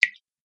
دانلود صدای چکه قطره آب از ساعد نیوز با لینک مستقیم و کیفیت بالا
جلوه های صوتی